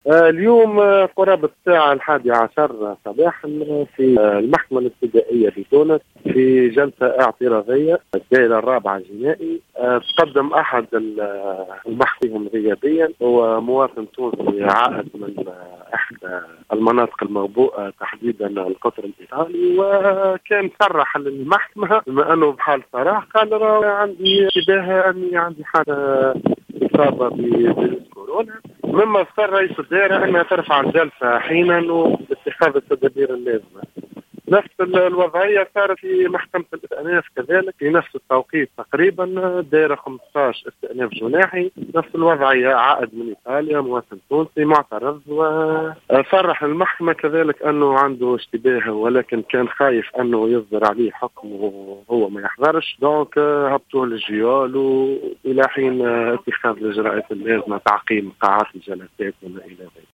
وفي تصريح للجوهرة أف أم